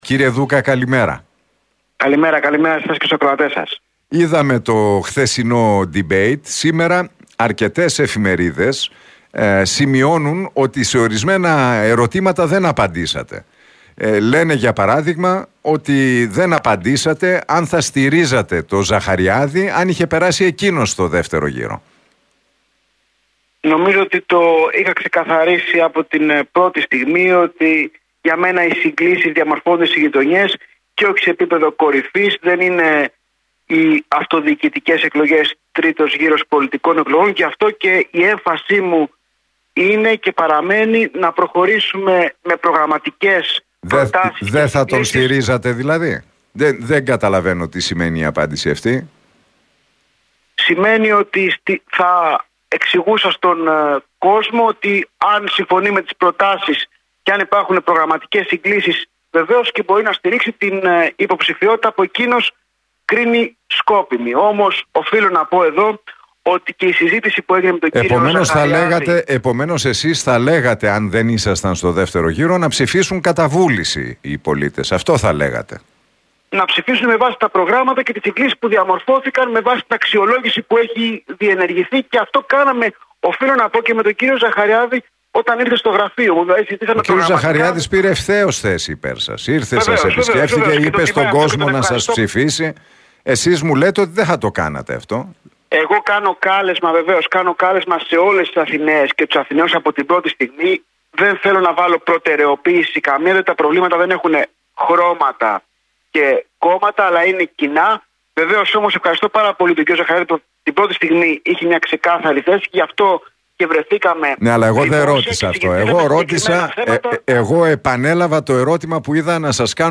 «Για μένα οι συγκλήσεις διαμορφώνονται στις γειτονιές και όχι σε επίπεδο κορυφής. Δεν είναι οι αυτοδιοικητικές εκλογές, Γ γύρος εθνικών εκλογών» δήλωσε ο Χάρης Δούκας, υποψήφιος για τον Δήμο Αθηναίων, στον Realfm 97,8 και στην εκπομπή του Νίκου Χατζηνικολάου.